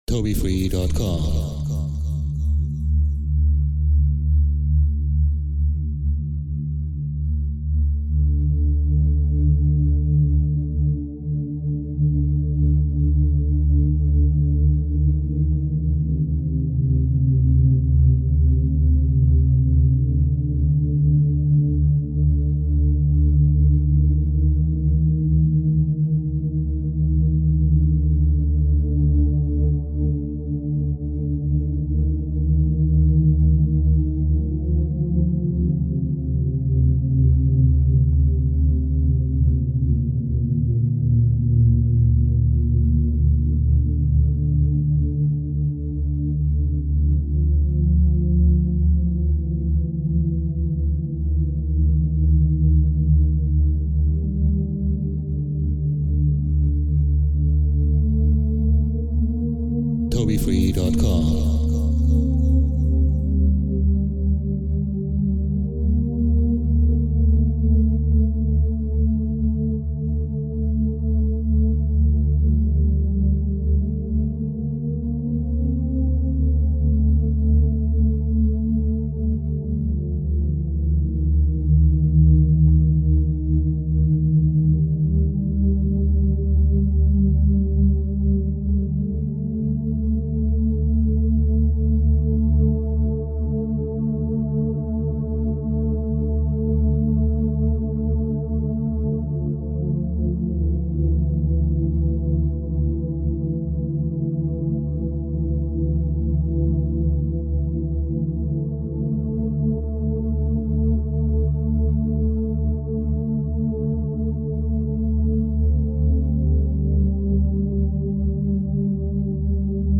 Genre: Synthwave.